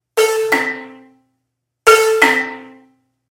In the image above (and you can click on it to see a larger version), I have moved my left hand "down" (1) a sixth; that is, first I struck A2 and then I struck C1.